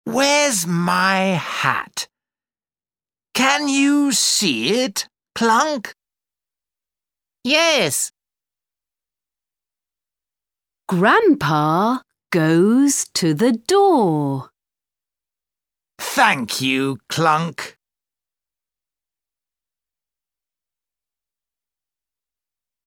Track 4 Where's My Hat British English.mp3